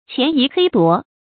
潛移嘿奪 注音： ㄑㄧㄢˊ ㄧˊ ㄏㄟ ㄉㄨㄛˊ 讀音讀法： 意思解釋： 見「潛移默奪」。